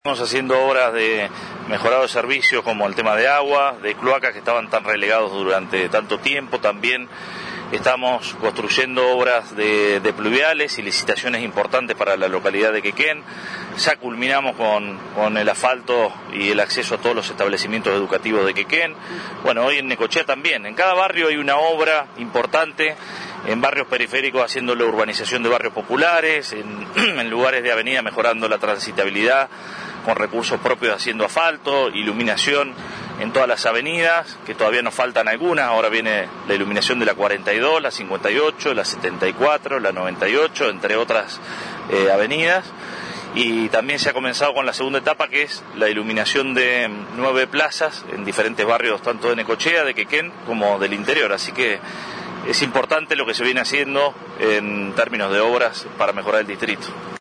En ocasión de su recorrida por la creciente obra del playón deportivo en el predio de la exempresa Raiser, en el barrio Estación Quequén, el intendente Arturo Rojas puso énfasis sobre el desarrollo de otras obras públicas que se van haciendo realidad en simultáneo.